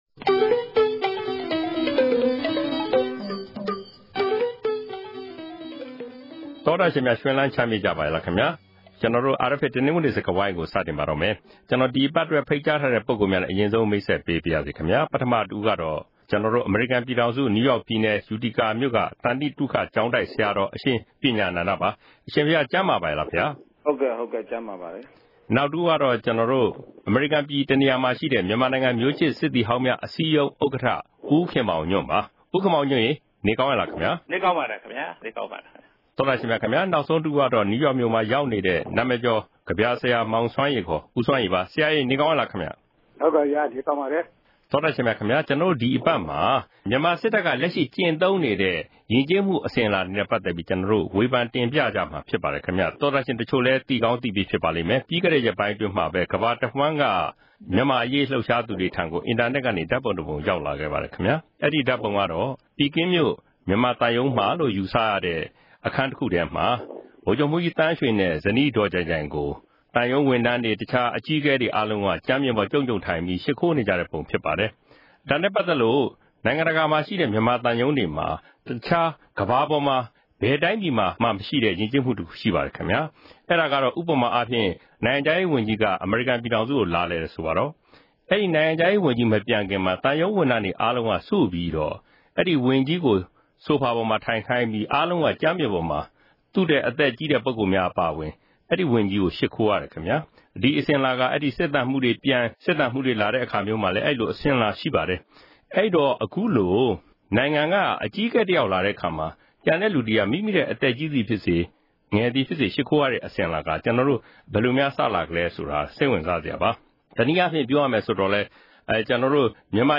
တနင်္ဂနွေစကားဝိုင်း။